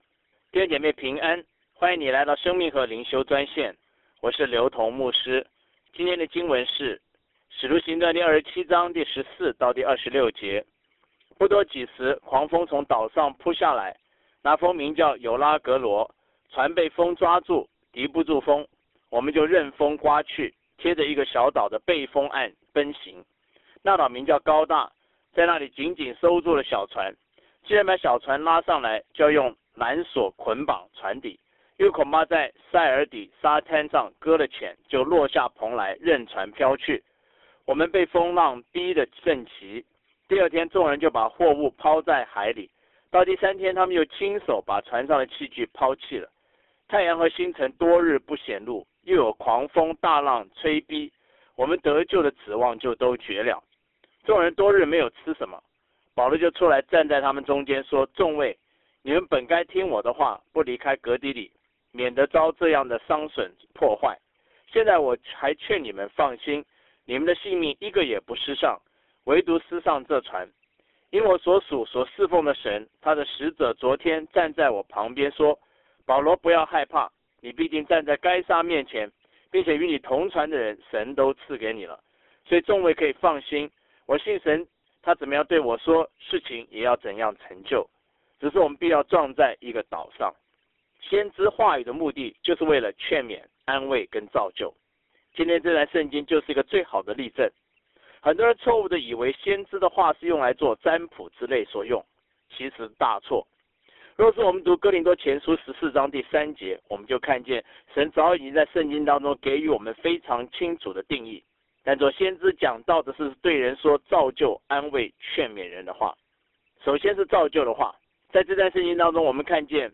以生活化的口吻带领信徒逐章逐节读经